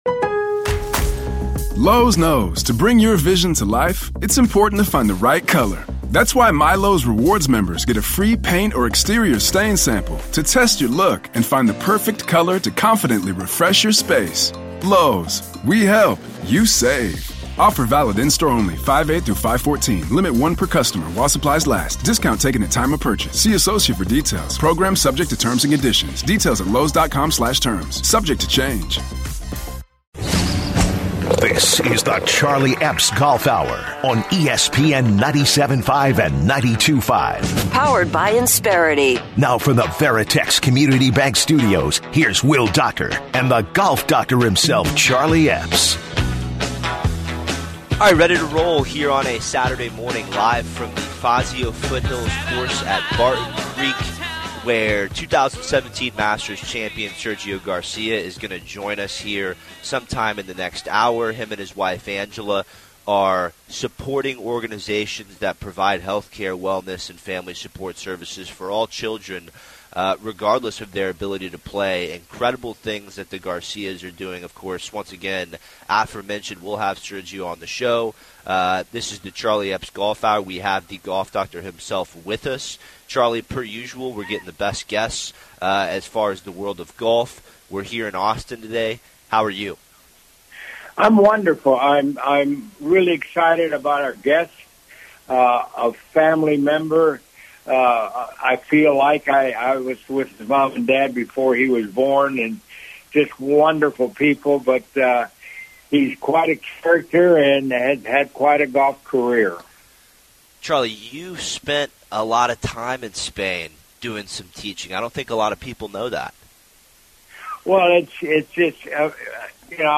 Share Facebook X Subscribe Next 2017 Masters Champion Sergio Garcia joins the show LIVE from Barton Creek!